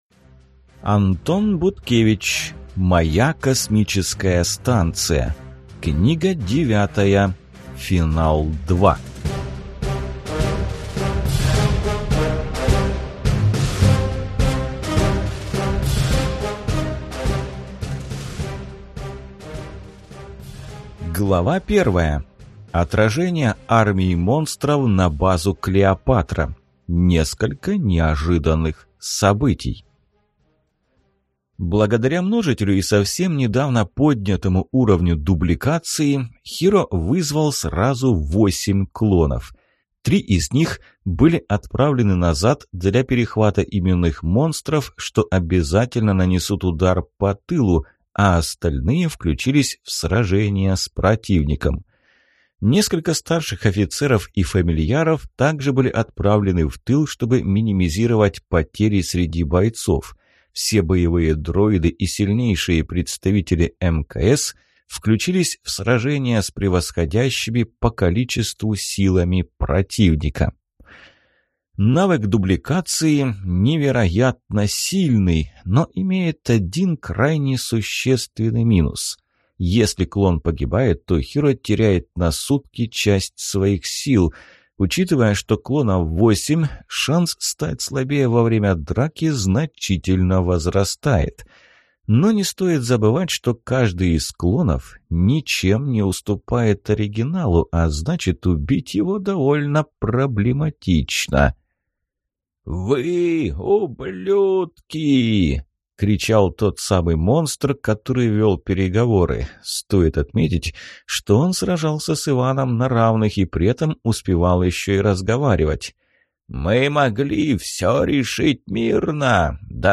Аудиокнига Моя Космическая Станция. Книга 9. Финал 2 | Библиотека аудиокниг